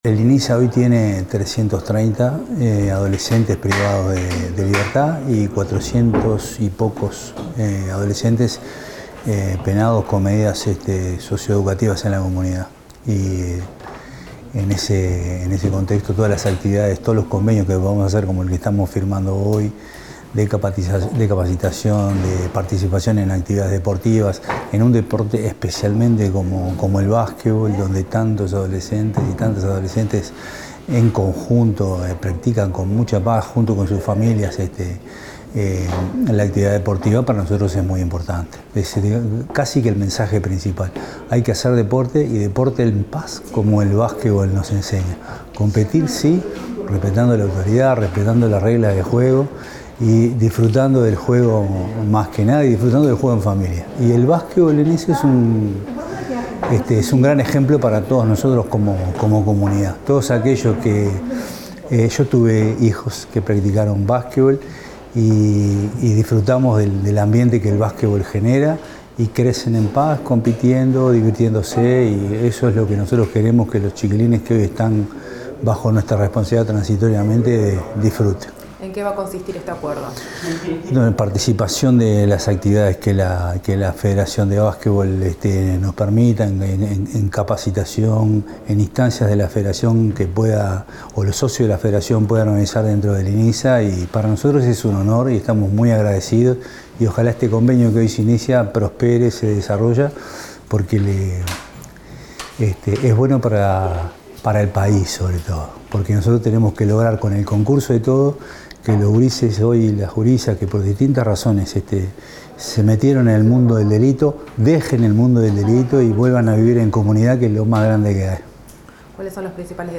Declaraciones del presidente del Inisa, Jaime Saavedra